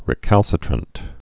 (rĭ-kălsĭ-trənt)